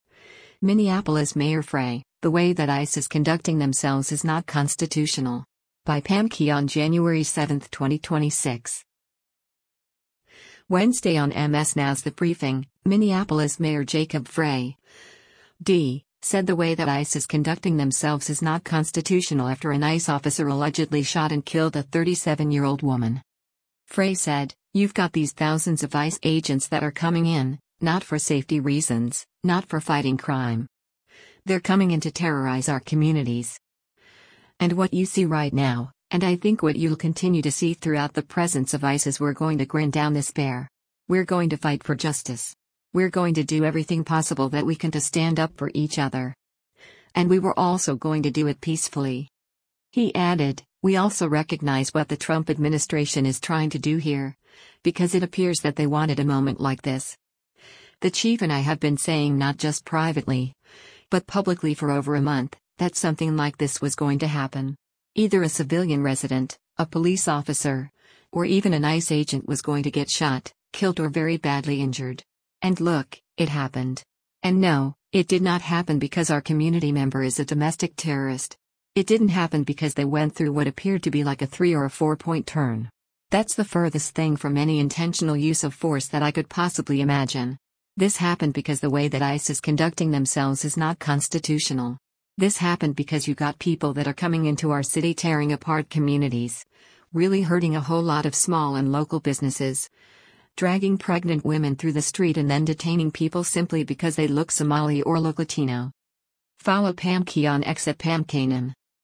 Wednesday on MS NOW’s “The Briefing,” Minneapolis Mayor Jacob Frey (D) said “the way that ICE is conducting themselves is not constitutional” after an ICE officer allegedly shot and killed a 37-year-old woman.